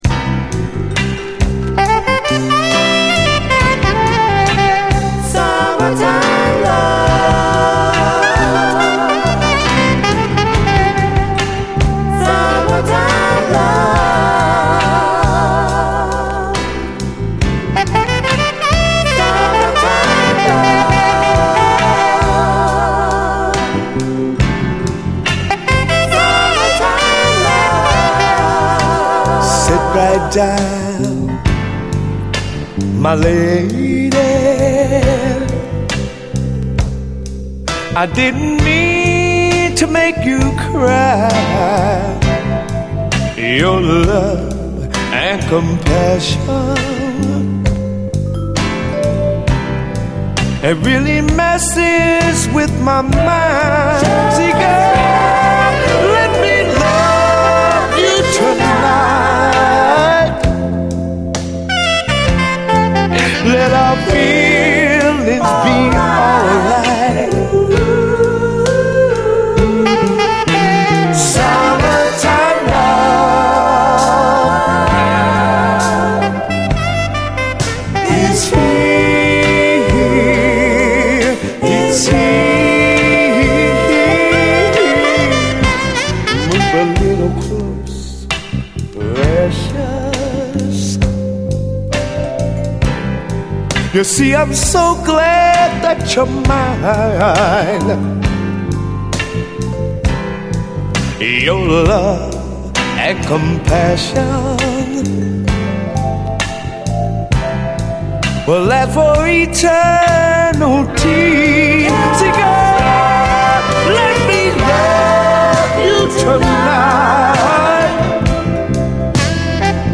様々なディスコ・ヒットの弾き直しトラックによるB級カヴァーやディスコ・ラップ群が嬉しい！